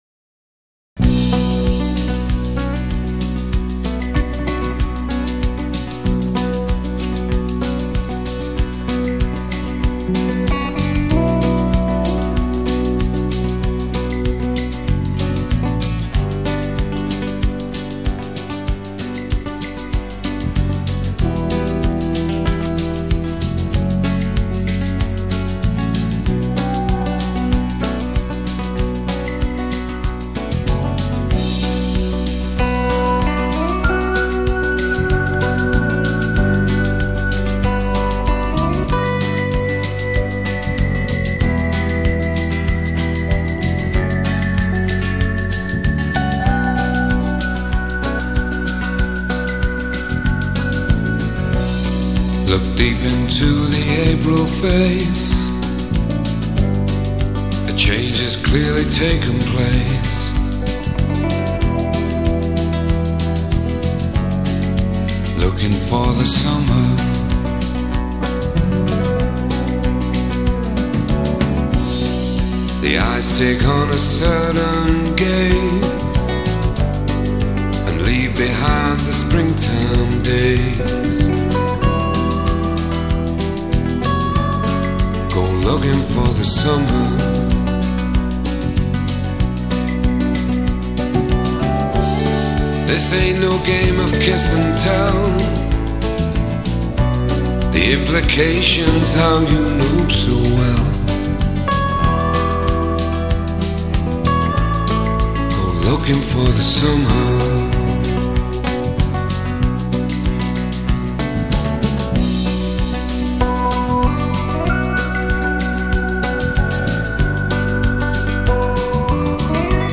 Жанр: foreignbard
СТАРЫЕ ЗАРУБЕЖНЫЕ БАЛЛАДЫ